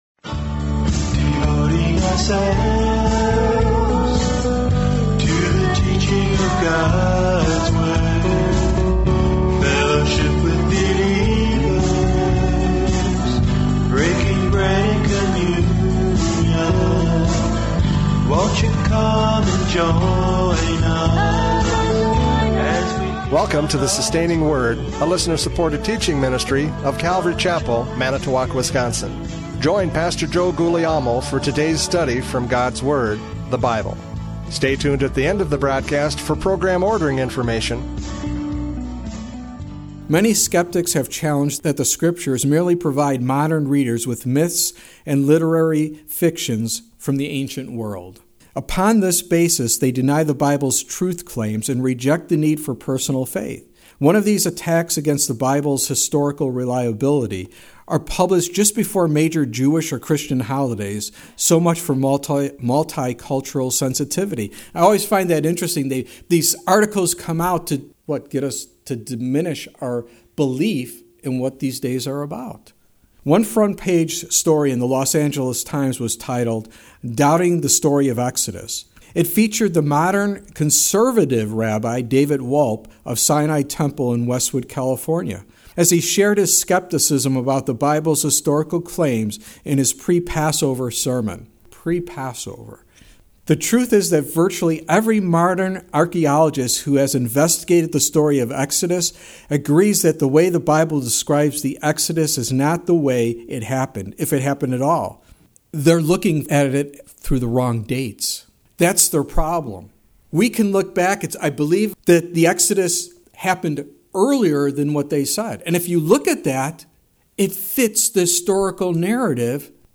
John 12:9-11 Service Type: Radio Programs « John 12:9-11 Destroying the Evidence!